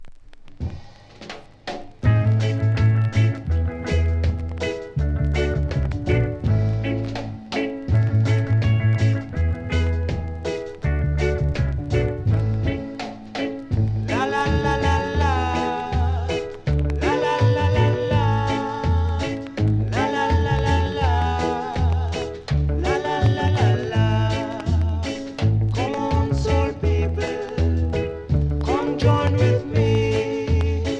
キズ多めでそれなりにノイズありますので試聴で確認下さい。